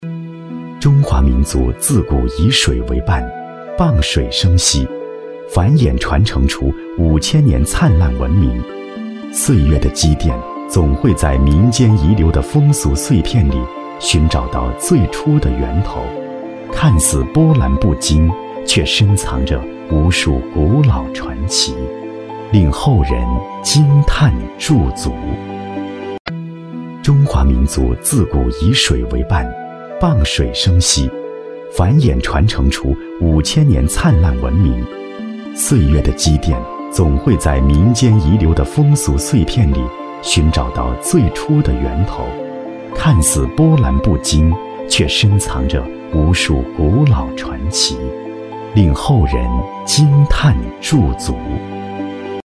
• 男S39 国语 男声 纪录片-民俗纪录片凌源高跷 低沉|大气浑厚磁性|沉稳